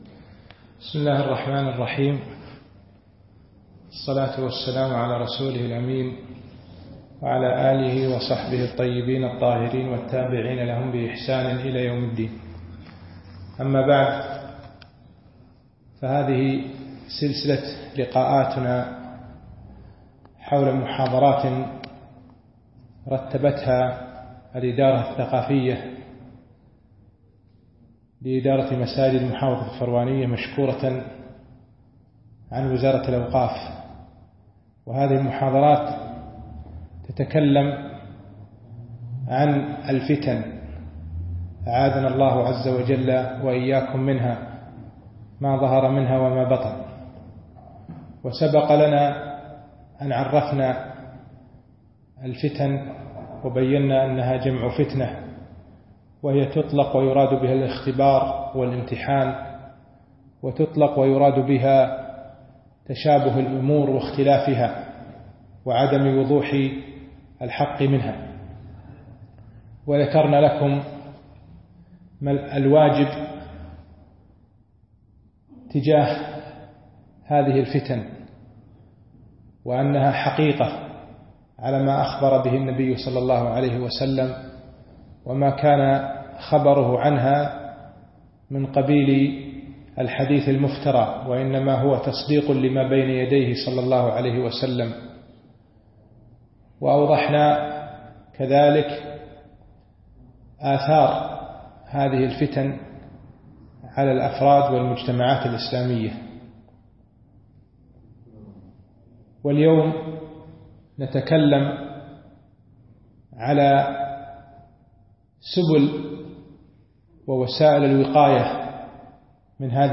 المحاضرة الثالثة - سبل الوقاية من الفتن